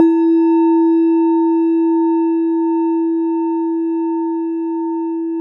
Index of /90_sSampleCDs/E-MU Formula 4000 Series Vol. 4 – Earth Tones/Default Folder/Japanese Bowls